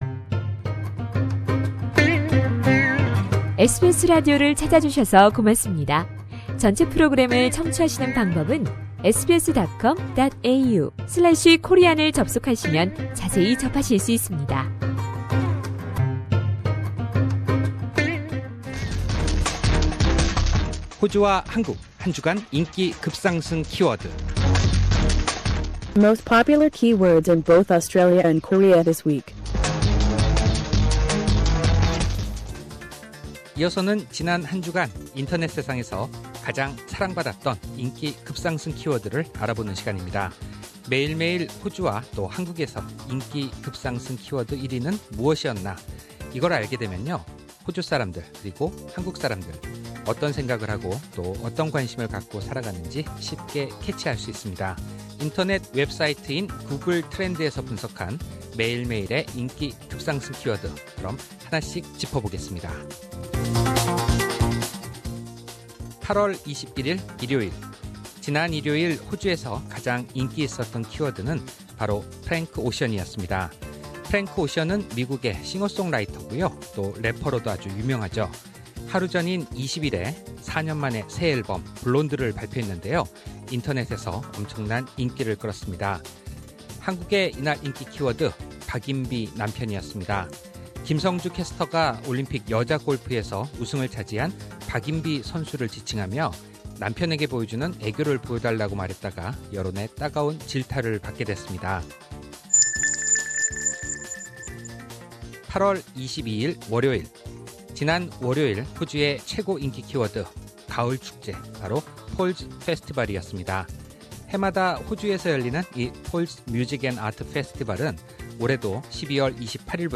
상단의 다시 듣기 (Podcast)를 클릭하시면 라디오 방송을 다시 들으실 수 있습니다. 매일매일 호주와 한국의 인기 급상승 키워드를 알아보고, 해당 키워드가 1위를 차지하게 된 자세한 설명을 이야기해 드립니다.